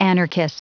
Prononciation du mot anarchist en anglais (fichier audio)
Prononciation du mot : anarchist